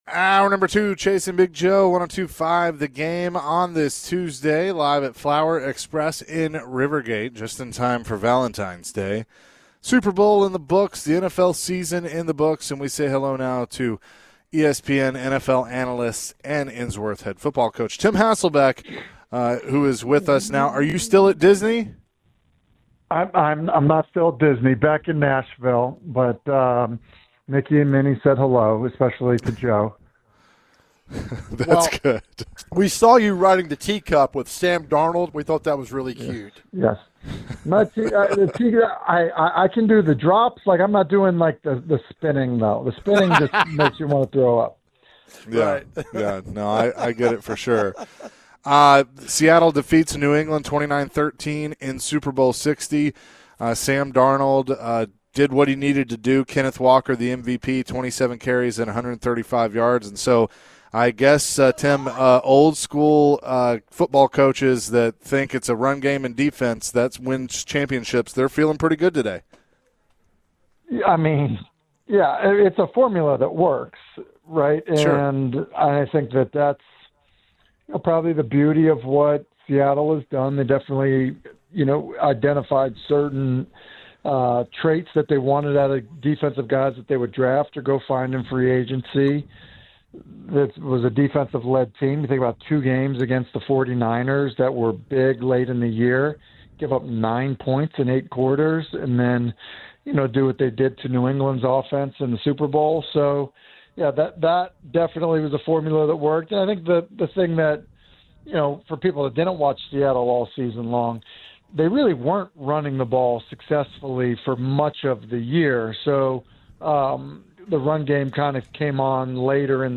The guys chatted with ESPN NFL analyst Tim Hasselbeck. Tim shared his thoughts on Drake Maye's performance at the Super Bowl. Tim also shared his thoughts on a possible trade for Will Levis to the Rams.